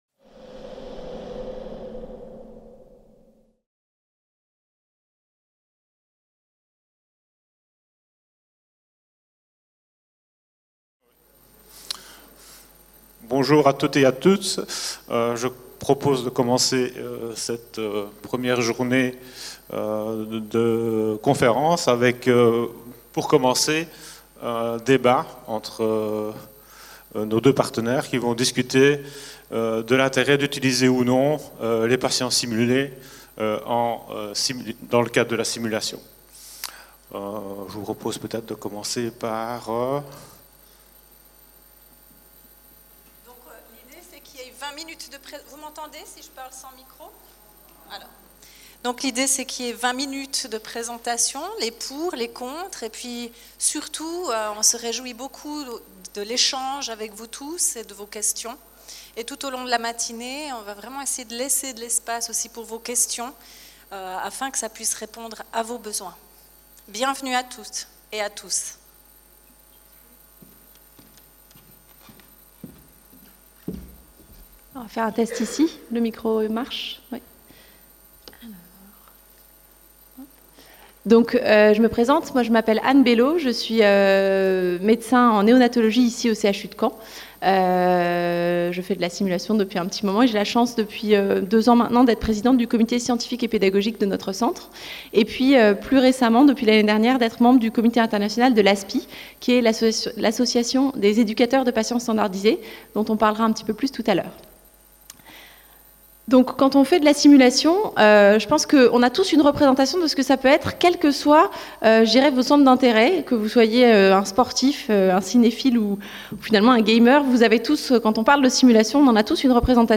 SOFRASIMS 2018 | 03 - Un patient Simulé ? – Débat « Moi, jamais !